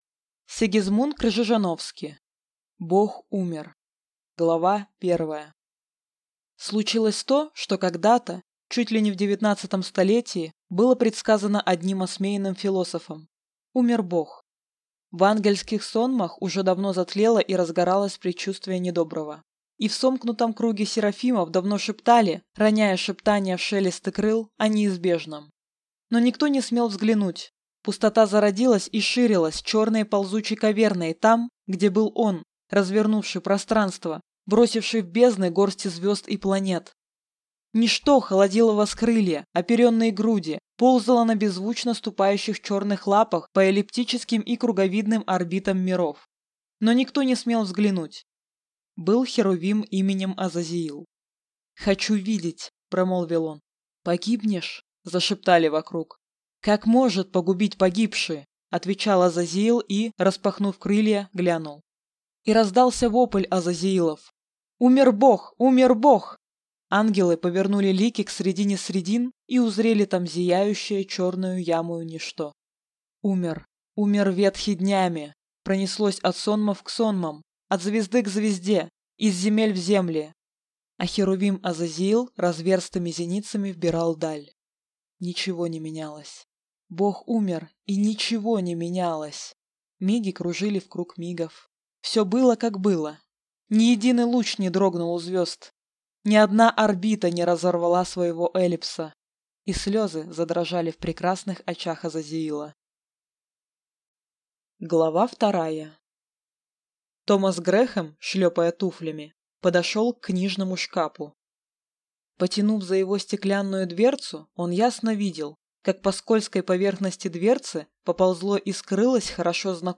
Аудиокнига Бог умер | Библиотека аудиокниг